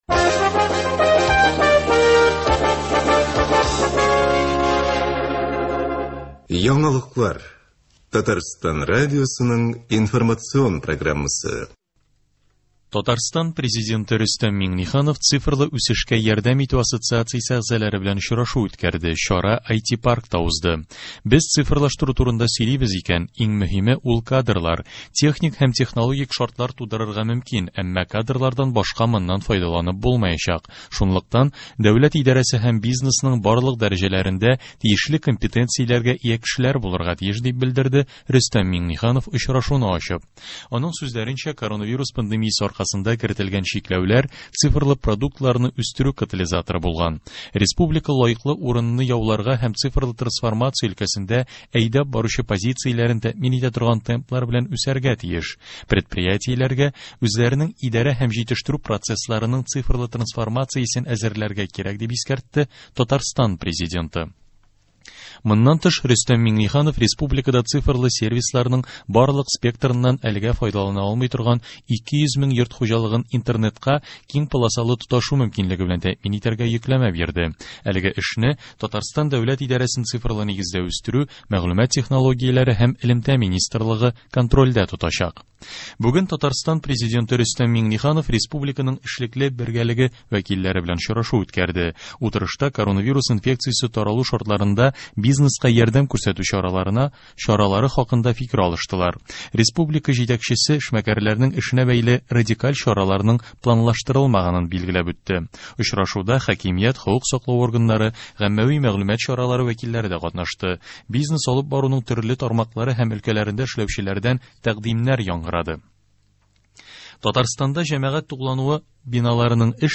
Яңалыклар (16.11.20)